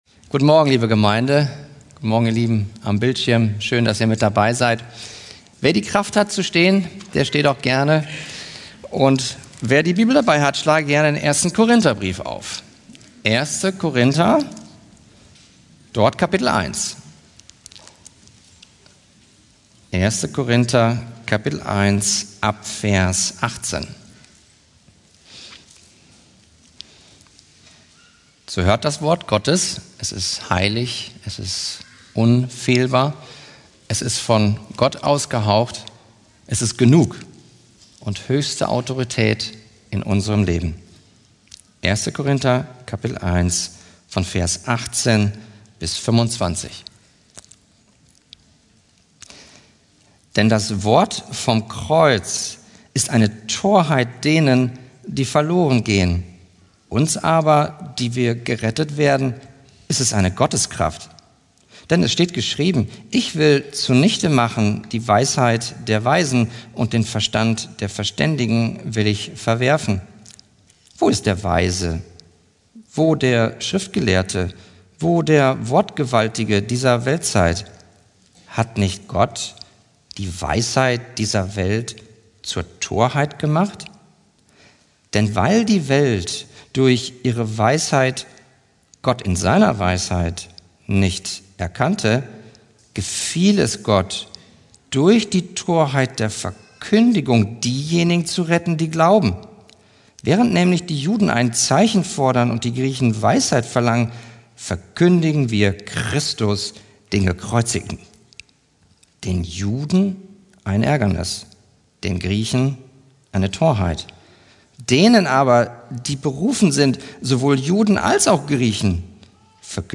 Karfreitag-Predigt vom 03.04.2026 | 1.